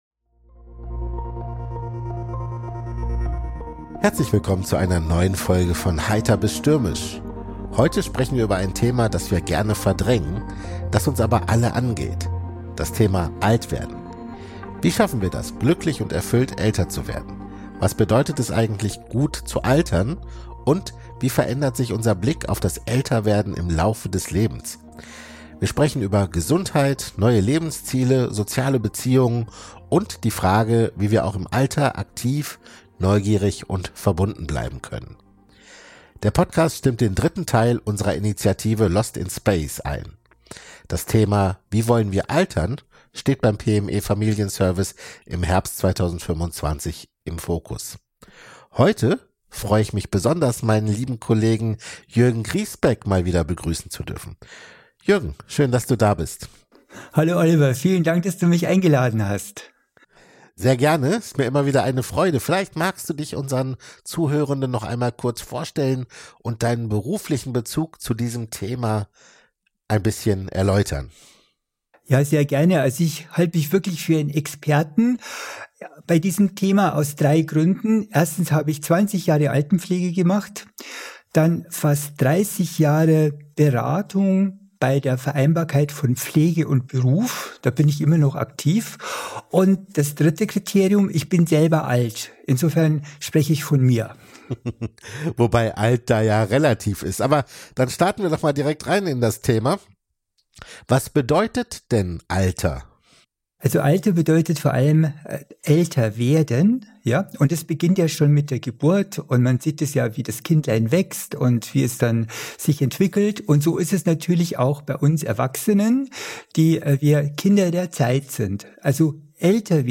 Wir sprechen mit Expert:innen und geben Ihnen praktische Tipps an die Hand, damit Sie besser mit Krisen und Herausforderungen umgehen können.